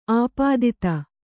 ♪ āpādita